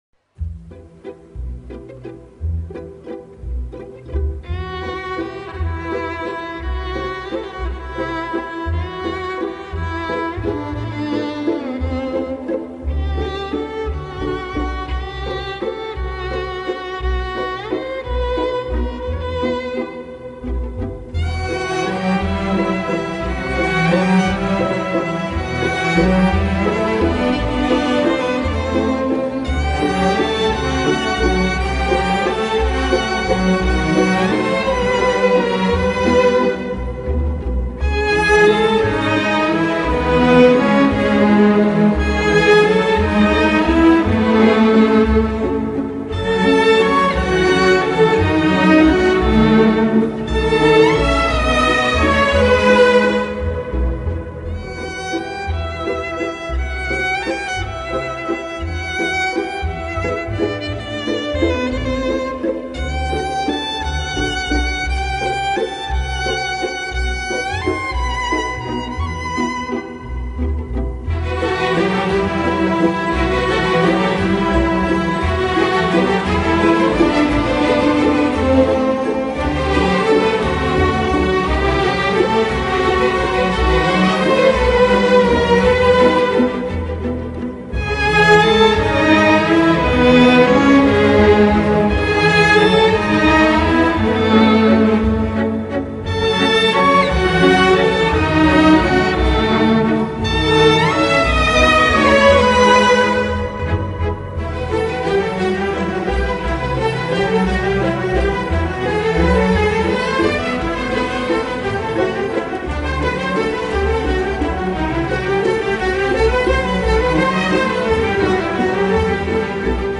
вальса бабочек
потому как-минорчик!)))))))))))